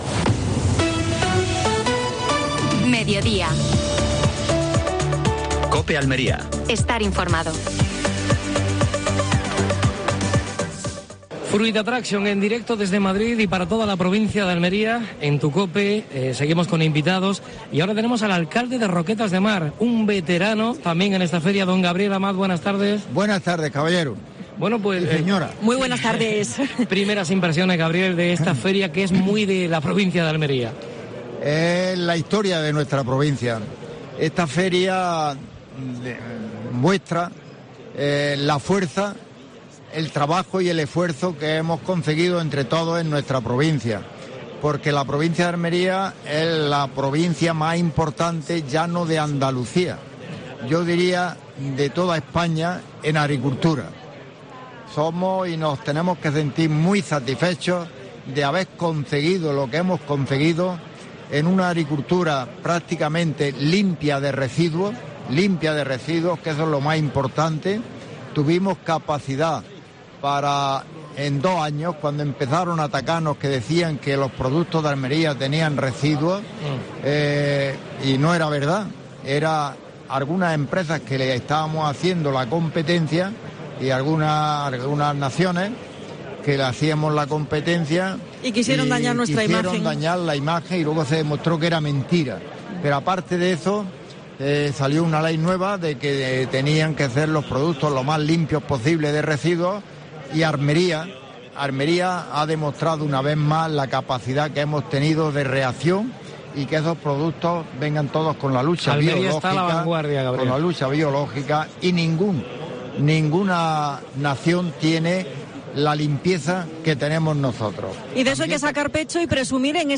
AUDIO: Programa especial desde Fruit Attraction (Madrid). Entrevista a Gabriel Amat (alcalde de Roquetas de Mar).